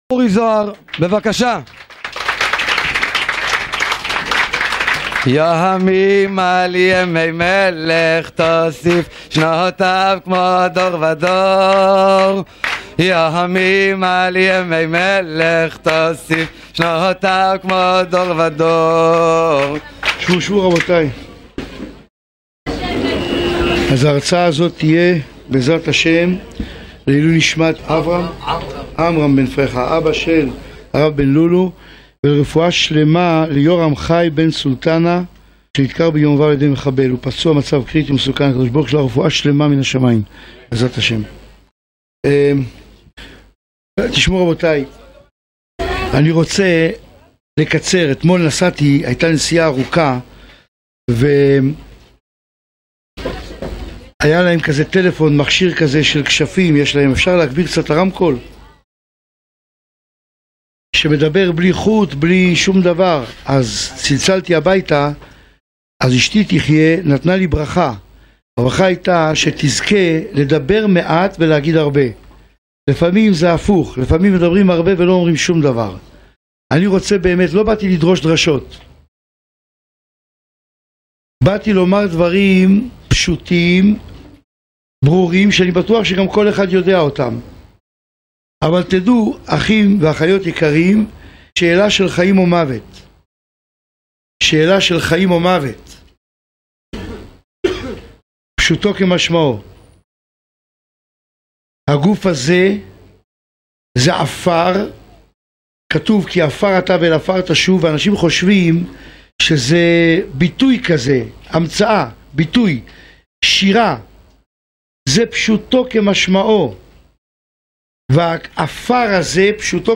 _הרב אורי זוהר בדרשה מרתקת!  למה באתי לעולם.mp3